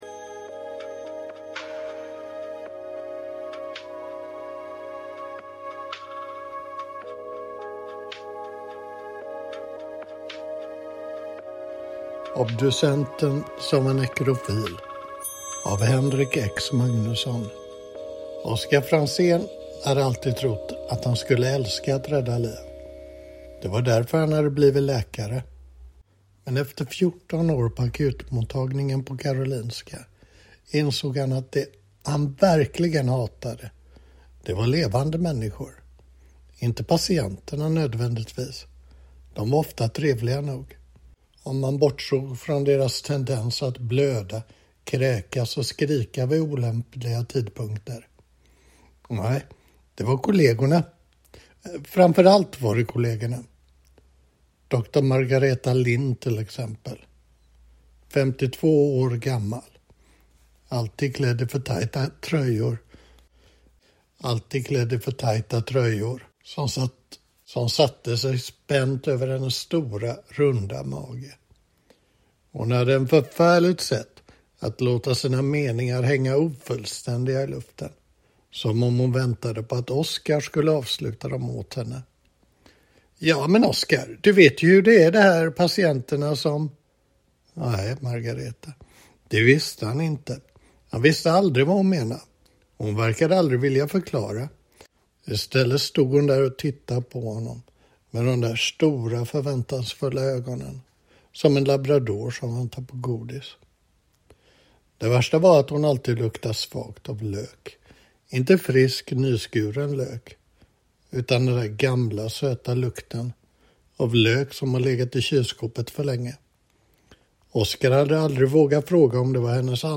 Obducenten som var nekrofil - och 8 andra bisarra noveller i mästarklass. (ljudbok